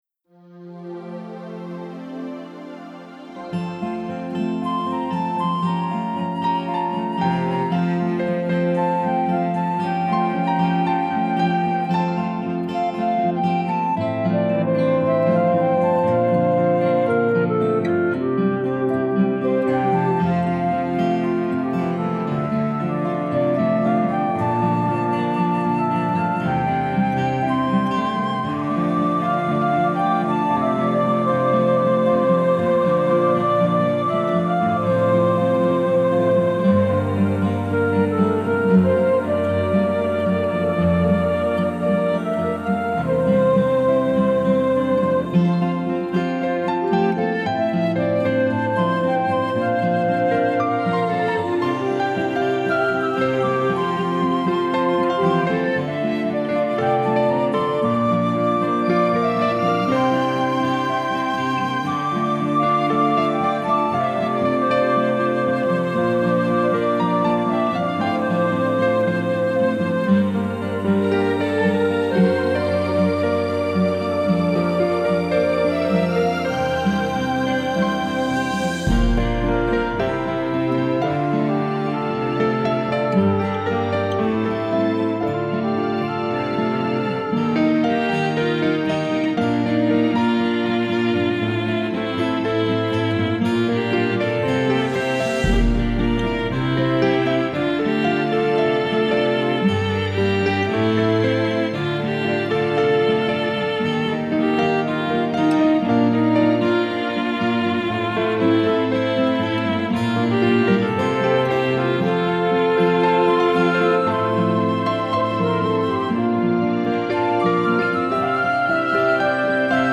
Let these instrumentals take you on a musical journey of healing with their touching melodies.
Later on, I gravitated more to acoustic guitar, piano and piano, and solo piano.